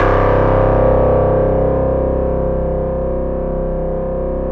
RESMET C1 -L.wav